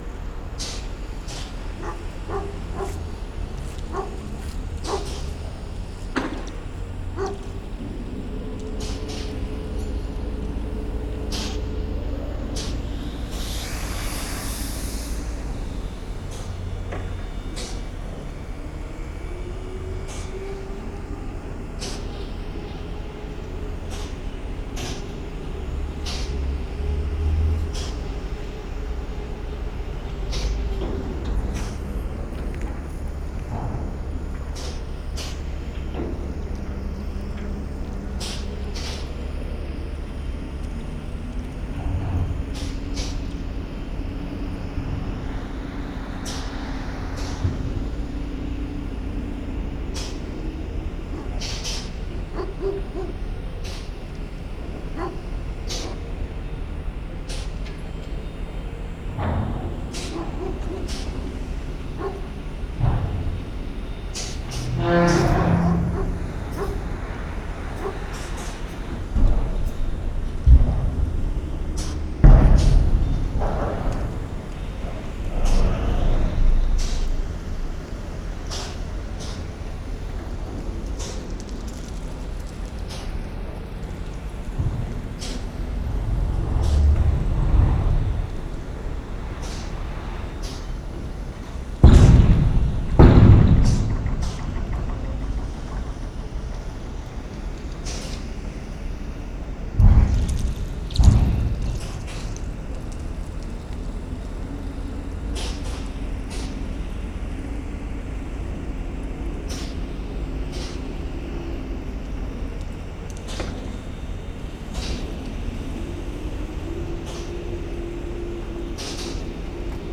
Lost Sound: Cranes unloading gravel from a barge. Rhythmic high sounds, occasional low crunches, barking dog, warning beep signal.
Soundfield microphone, Binaural decode.
Saturnushaven_1_2020_binaural.wav